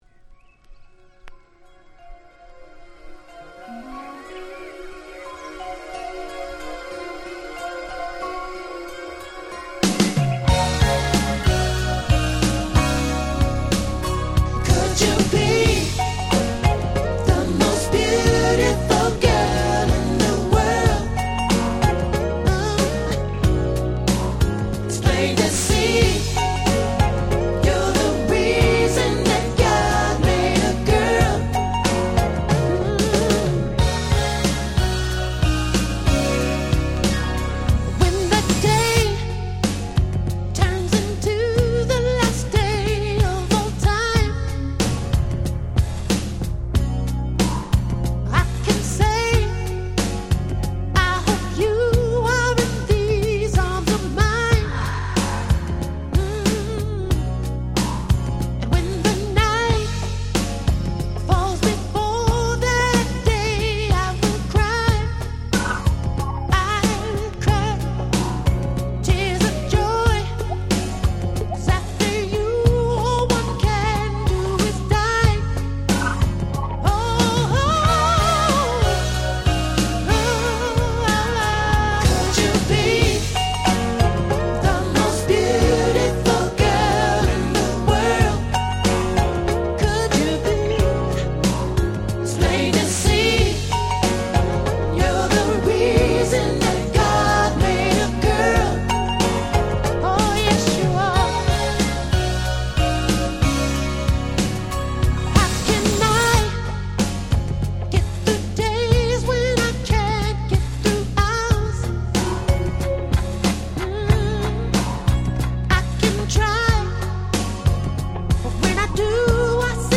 94' Big Hit R&B LP !!
キラキラで甘〜いMid Slowでございます。
90's バラード